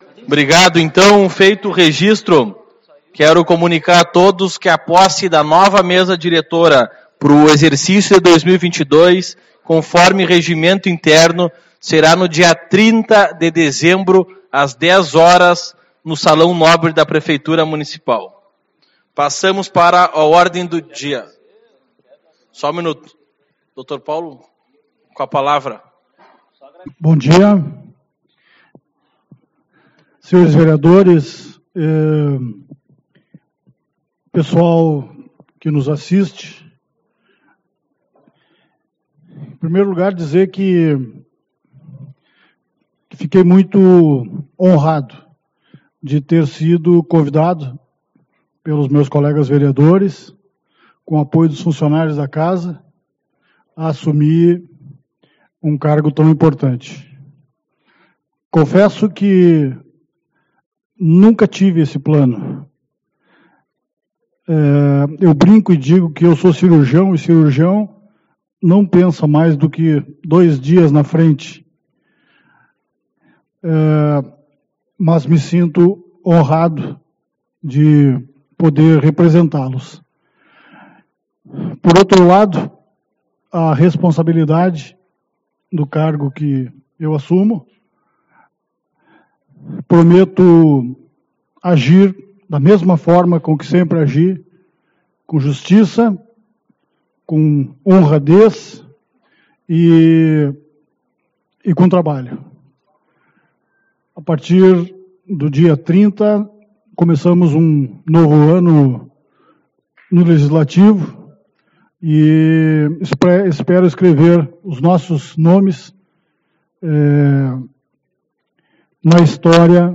21/12 - Reunião Ordinária